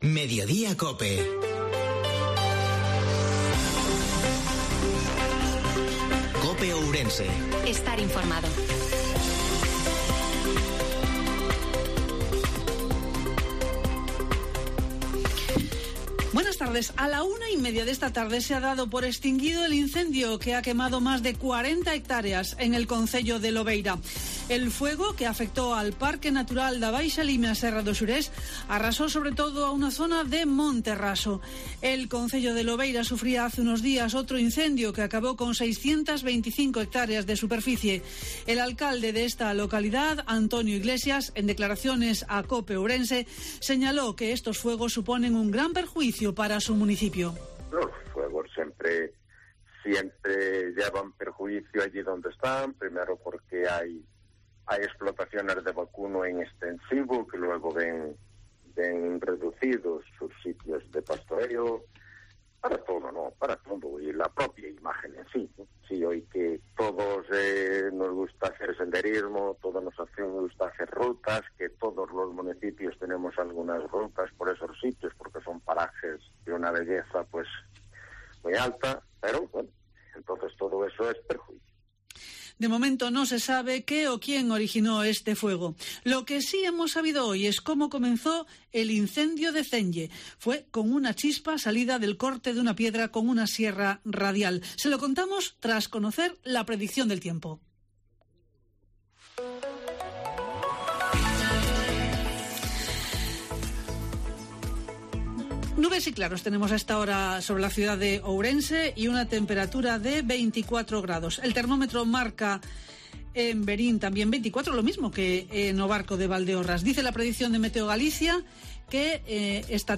INFORMATIVO MEDIODIA COPE OURENSE-02/09/2022